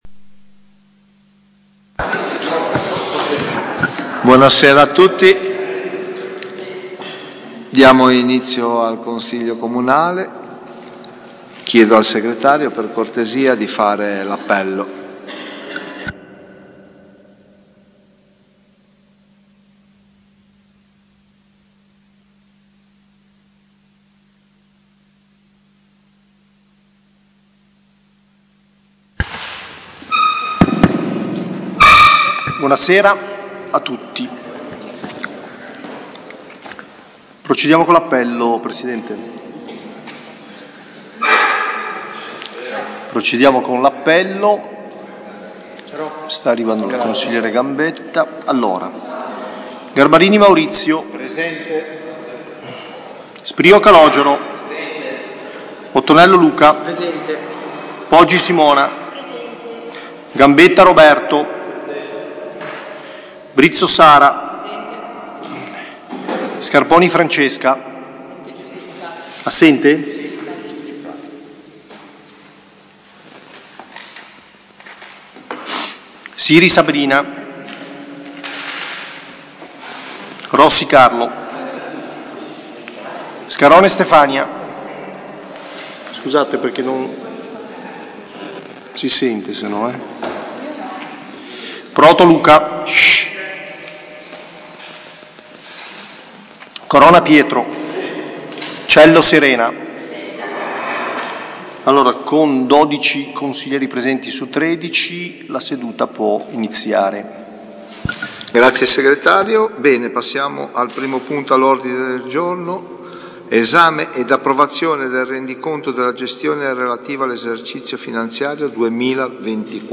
Seduta del Consiglio comunale di mercoledì 30 aprile 2025, alle 21.00, presso l’Auditorium comunale, in seduta ordinaria, sono stati trattati i seguenti argomenti: Esame ed approvazione del Rendiconto della gestione relativo all'esercizio finanziario 2024.